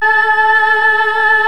M CHOIR  3.1.wav